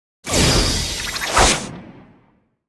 SFX回旋飞剑魔法技能音效下载
SFX音效